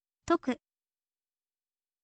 toku